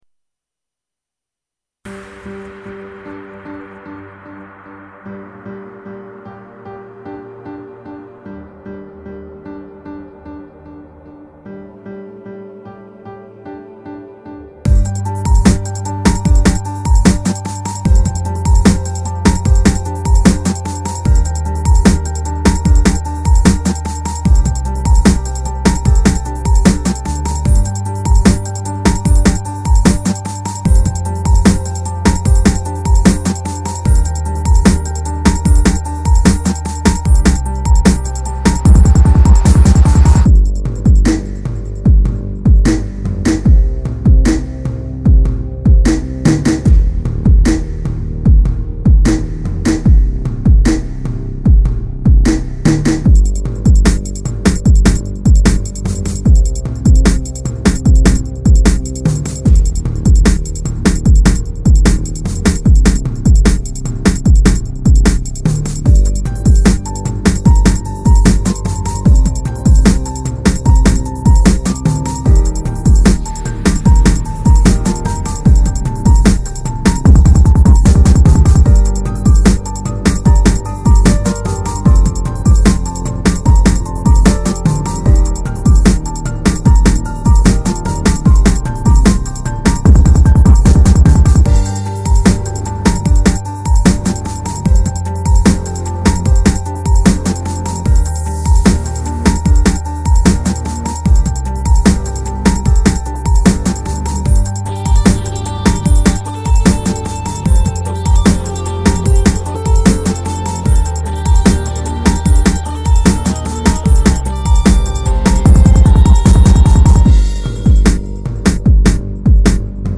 دانلود بیت رپ